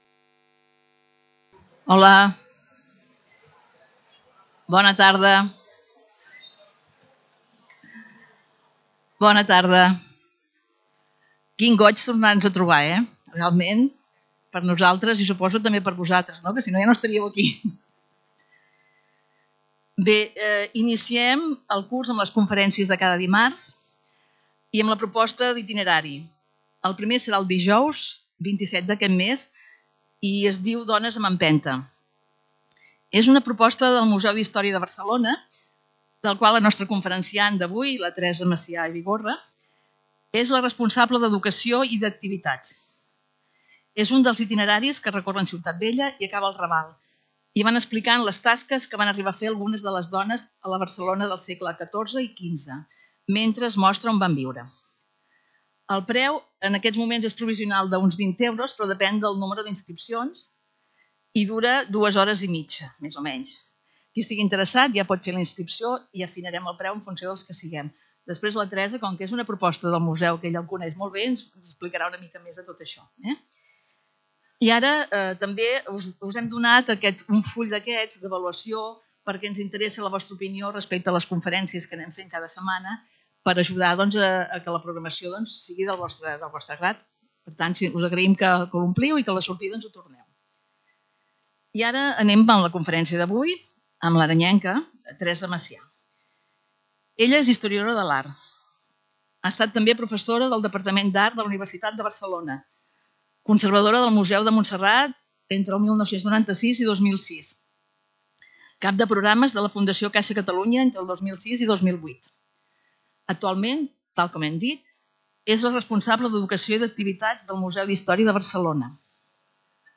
Era la primera conferència del curs 2022-2023 que va ser seguida amb molt d’interès pels socis que van omplir totalment la sala Josep Maria Arnau i bona part de la sala noble de l’edificici Calisay.
Lloc: Centre Cultural Calisay